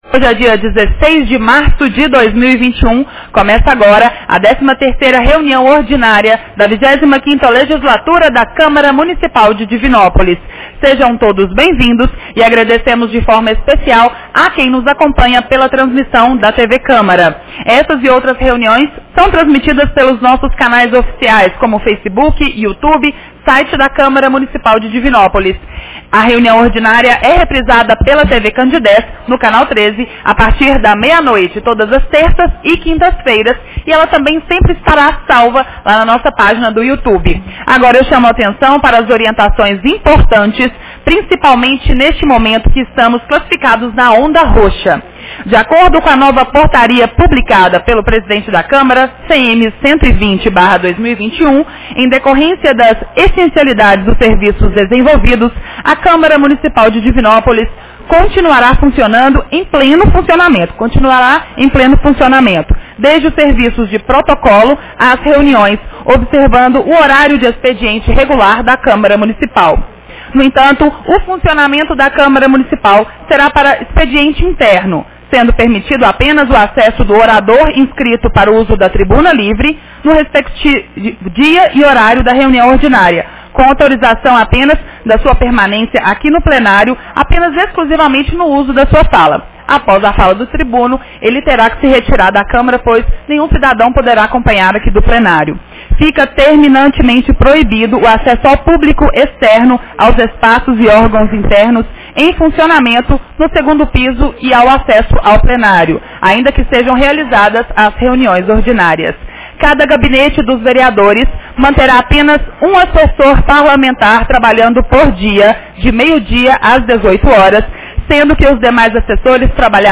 Reunião Ordinária 13 de 16 de março 2021